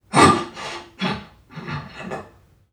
NPC_Creatures_Vocalisations_Robothead [32].wav